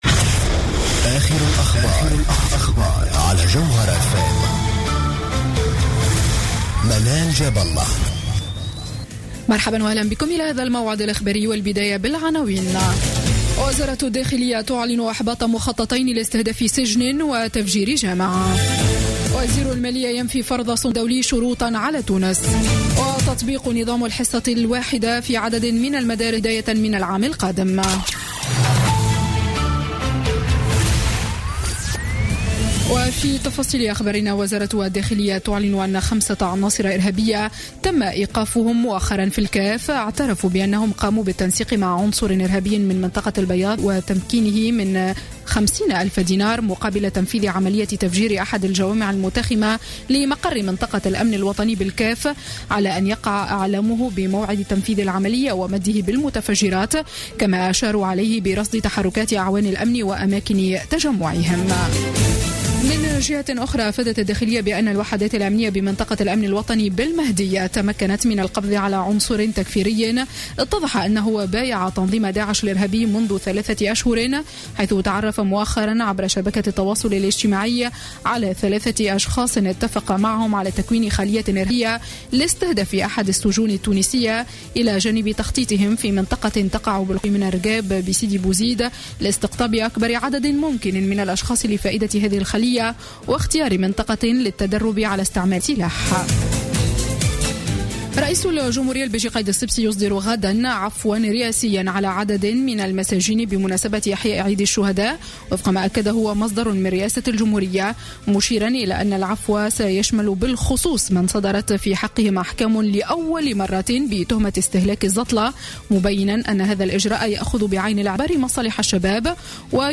Journal Info 19h00 du Vendredi 08 Avril 2016